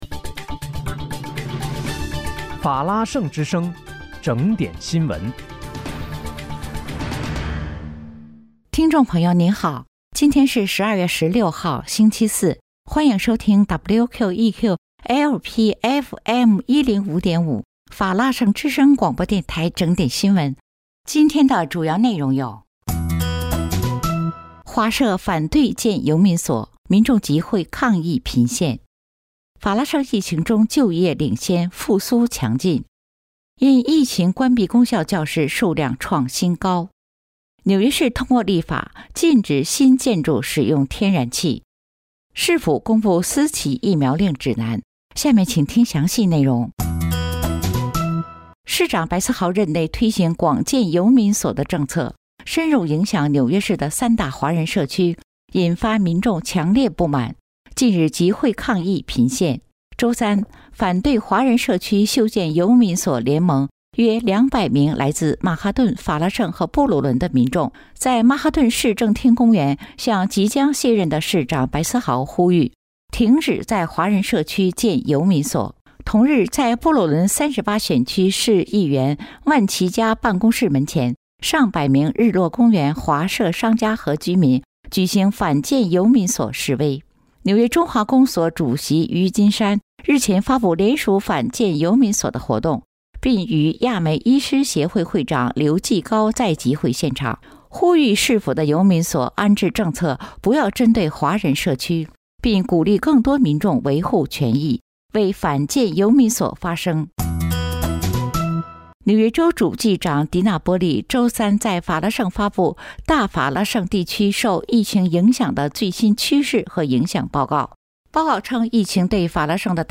12月16日（星期四）纽约整点新闻
听众朋友您好！今天是12月16号，星期四，欢迎收听WQEQ-LP FM105.5法拉盛之声广播电台整点新闻。